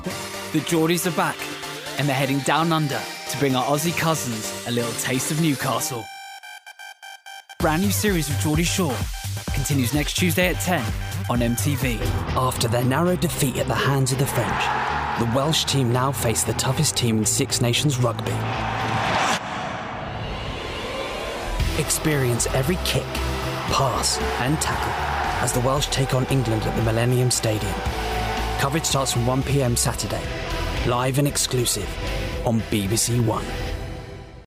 Promo Reel
RP ('Received Pronunciation')
Promo, Cool, Energetic, Confident, Bold